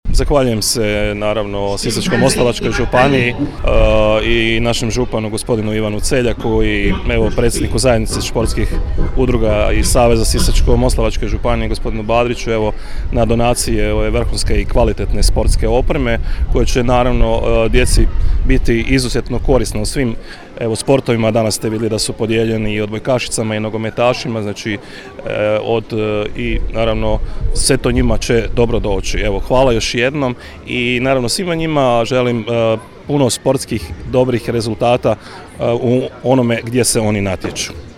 Načelnik Općine Lekenik Ivica Perović izrazio je zadovoljstvo ovom sportskom donacijom te se zahvalio